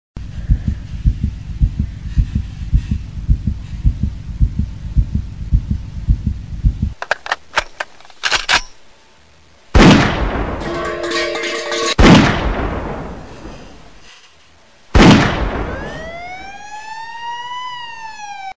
LATIDO DE CORAZON DISPAROS SIRENA
EFECTO DE SONIDO DE AMBIENTE de LATIDO DE CORAZON DISPAROS SIRENA
Latido_de_corazon_-_Disparos_-_Sirena.mp3